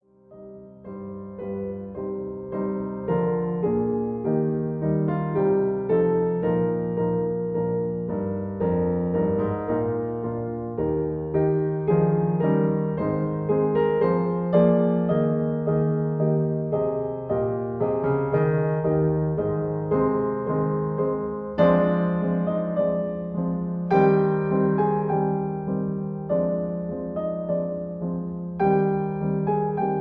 MP3 piano accompaniment in C minor